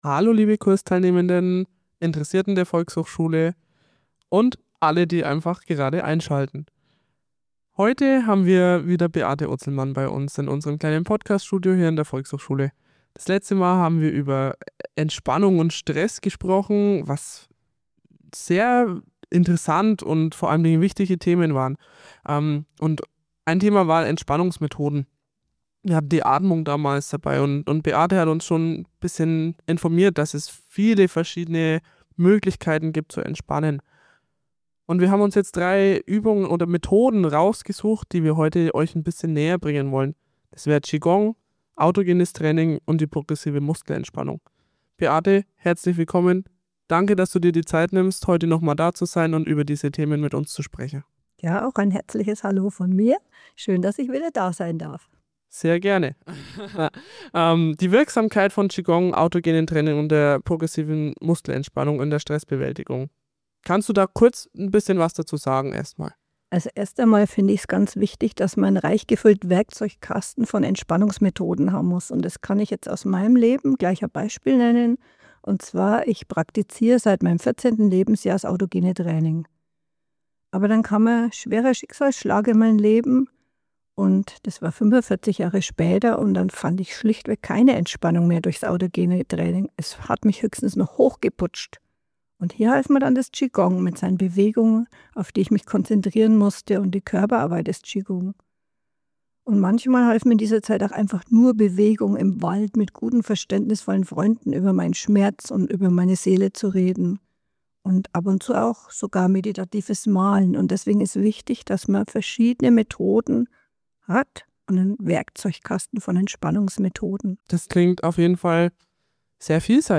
in unserem Podcaststudio der Volkshochschule